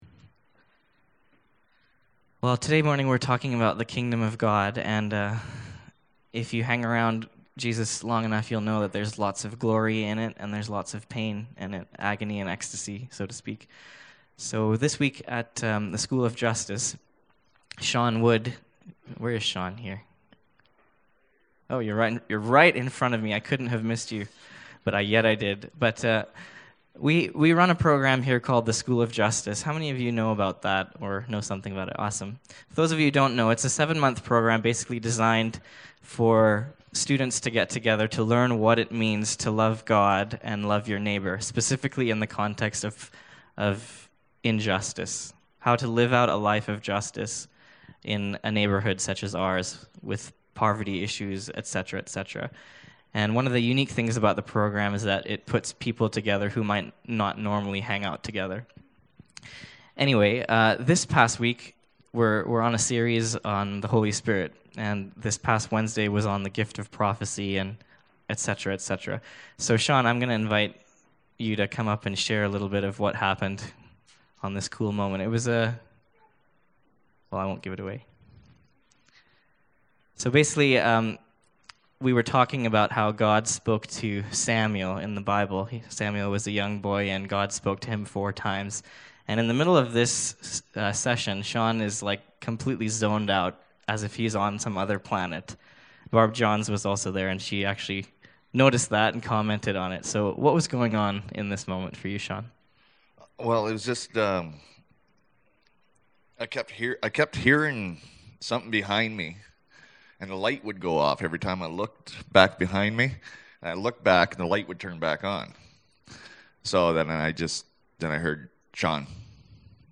Mark 1:15 Service Type: Downstairs Gathering Bible Text